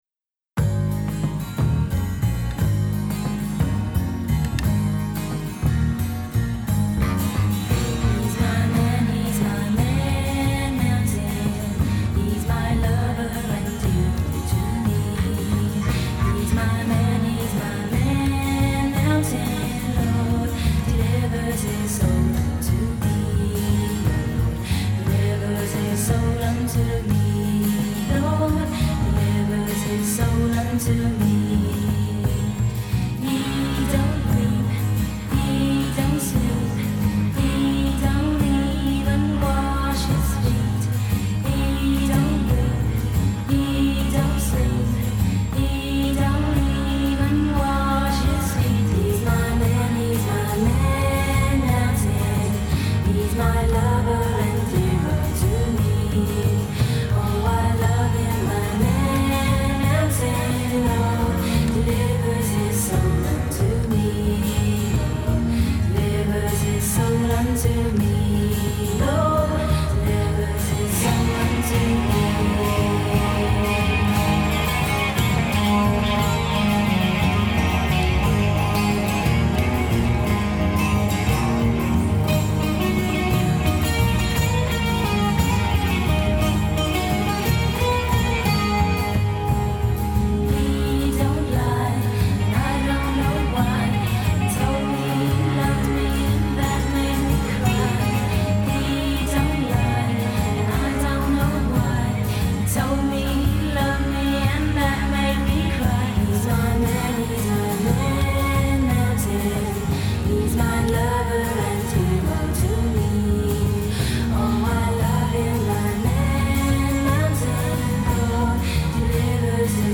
Here are five great new wave cuts from 1983.